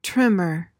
PRONUNCIATION: (TRIM-uhr) MEANING: noun: 1.